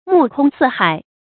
目空四海 注音： ㄇㄨˋ ㄎㄨㄙ ㄙㄧˋ ㄏㄞˇ 讀音讀法： 意思解釋： 四海：指全國各地。